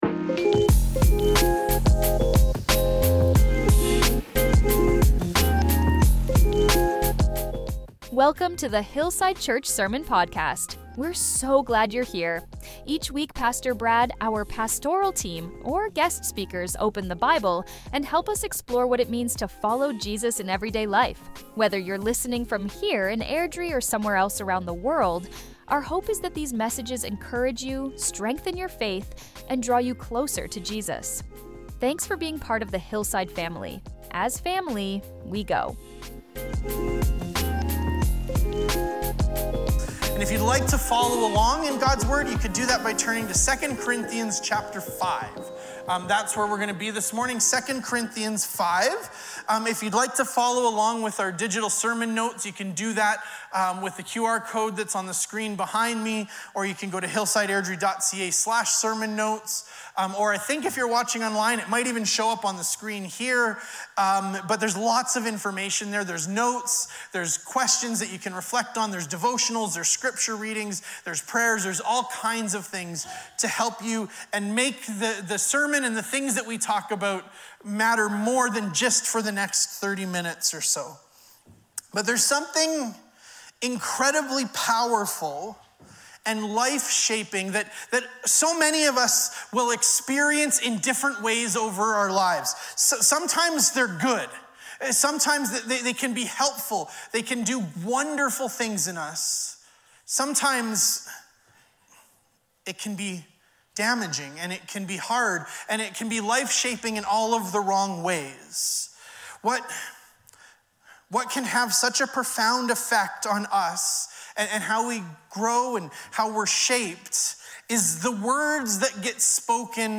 This sermon invited us to recognize the ways we can drift back into old identities—through familiarity, shame, lingering voices, or simply not knowing how to live differently—and to anchor ourselves again in what is true.